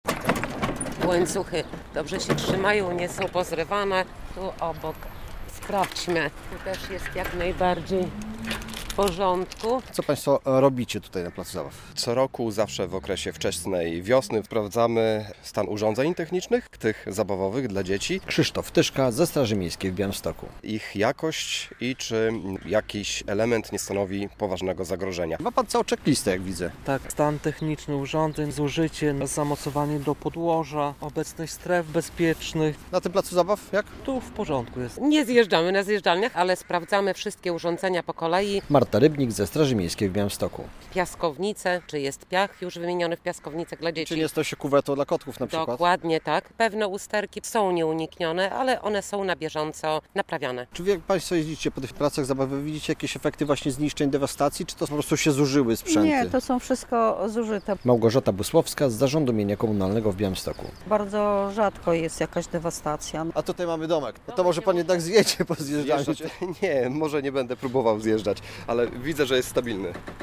W Białymstoku rozpoczęły się kontrole placów zabaw - relacja